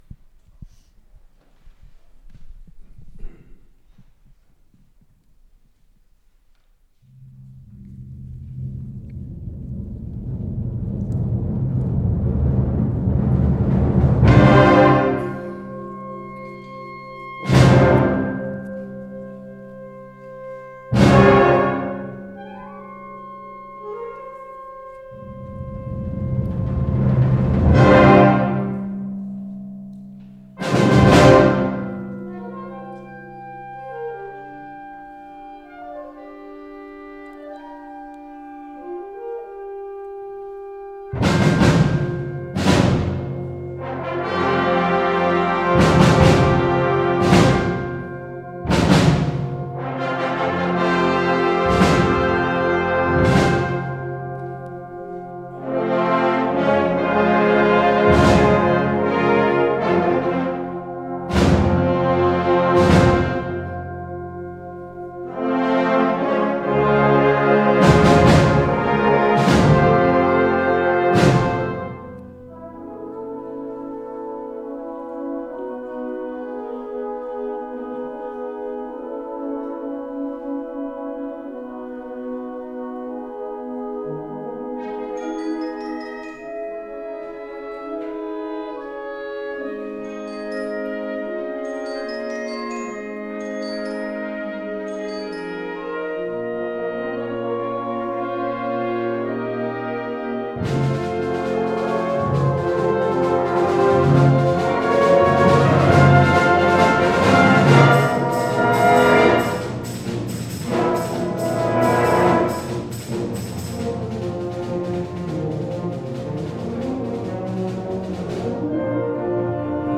Concertconcours Zutphen 2022